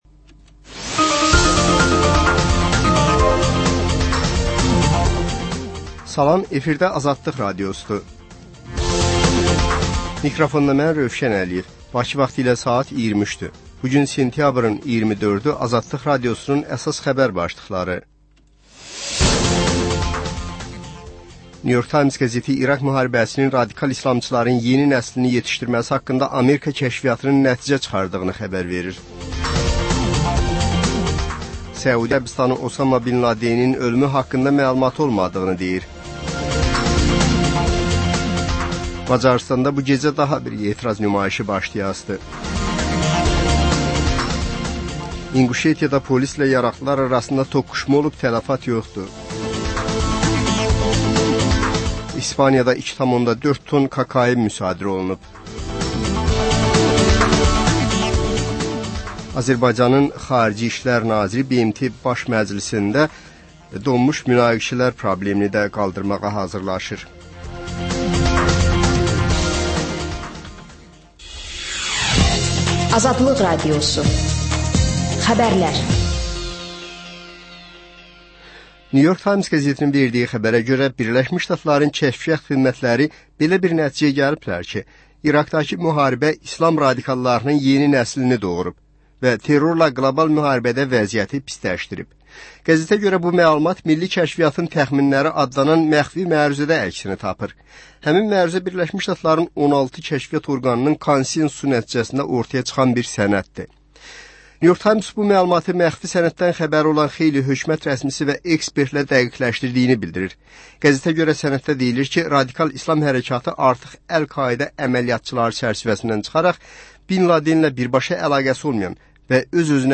Xəbərlər, reportajlar, müsahibələr. İZ: Mədəniyyət proqramı.